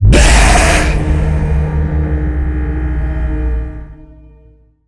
burer_telekinetic_0.ogg